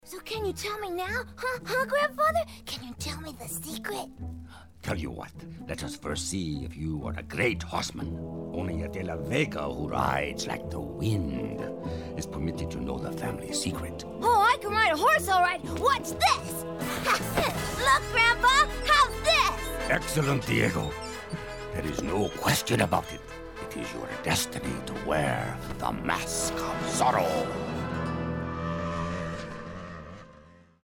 USA. All-American authority and highly versatile characters. 'Toon Pro.